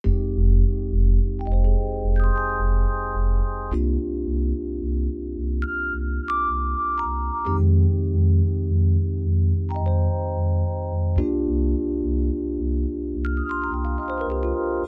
01 rhodes A.wav